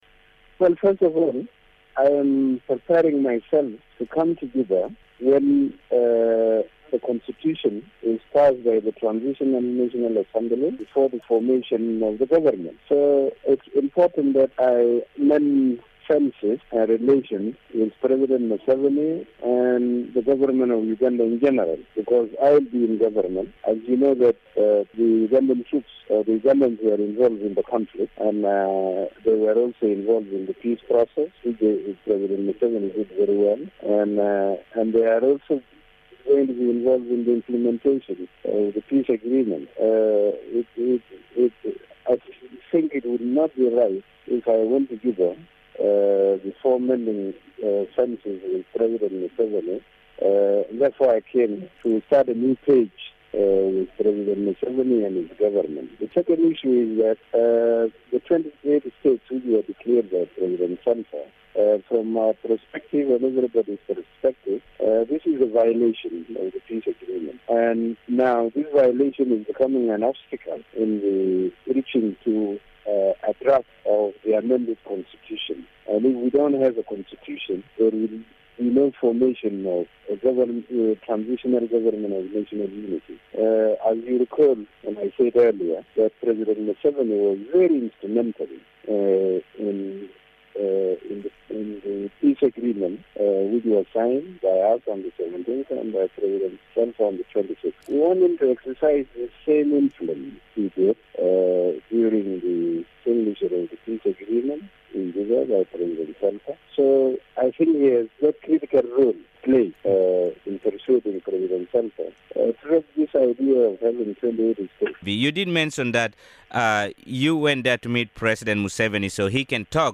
EXCLUSIVE: Riek Machar talks to Radio Miraya
Speaking exclusively to Radio Miraya from Kampala, Machar also said he would return to Juba after the formation of the Transitional National Legislative Assembly.